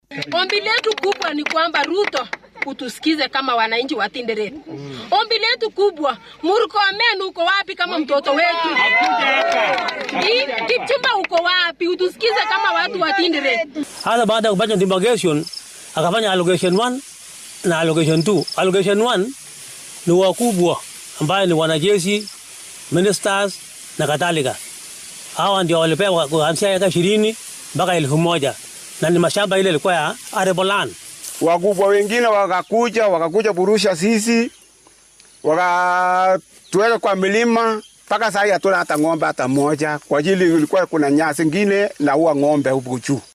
Qaar ka mid ah dadka deegaanka oo cabashadooda la wadaagay warbaahinta ayaa waxaa hadaladooda ka mid ahaa.